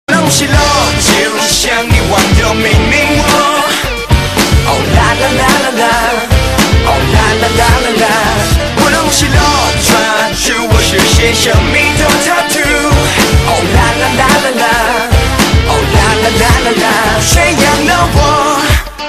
M4R铃声, MP3铃声, 华语歌曲 50 首发日期：2018-05-15 07:13 星期二